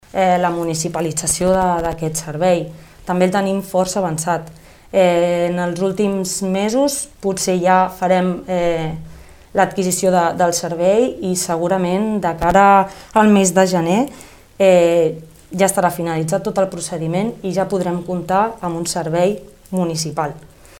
La regidora de contractació i transparència de l’Ajuntament de Tordera, Jennifer Jansa, ha avançat en declaracions a Ràdio Tordera que el servei municipal es podria posar en marxa el gener del 2022.